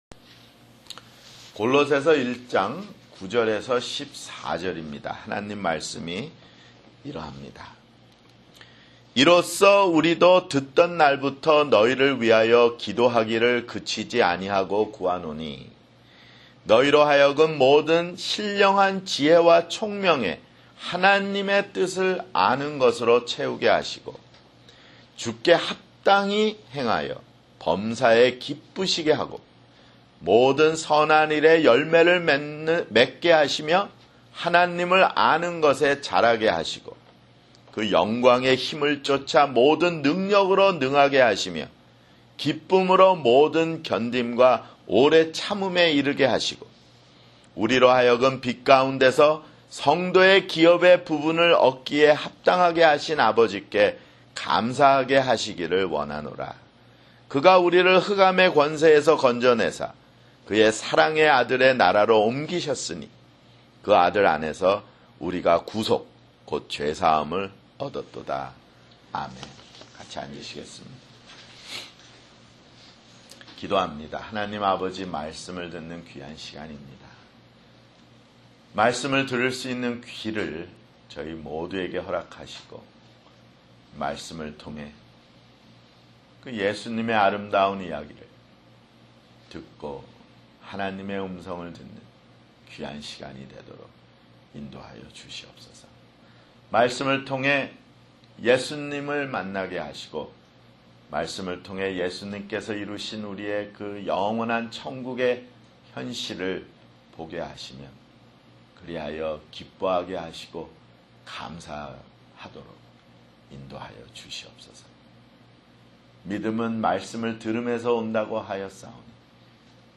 [주일설교] 골로새서 (16)